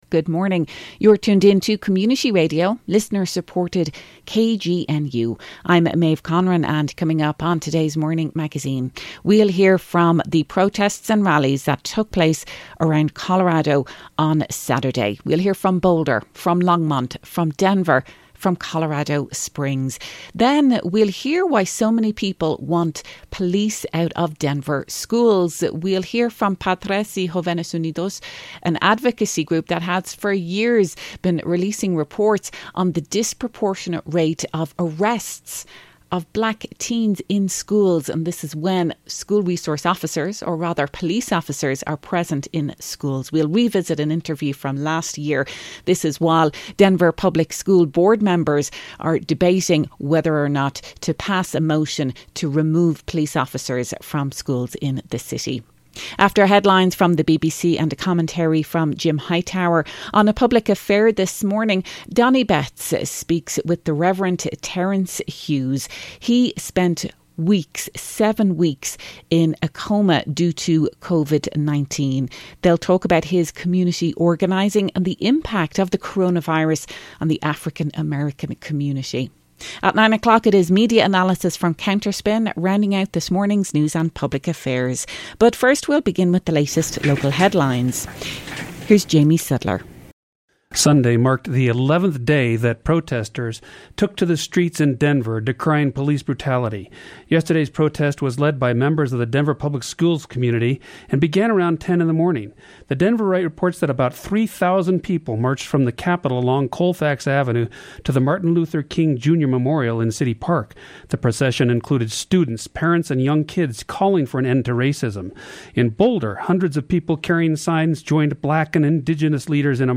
We hear voices and sounds from protests across the Front Range taking place over the weekend followed by a report on why action is being taken to remove police from Denver schools considering the disproportionate number of arrests of black students when police are present.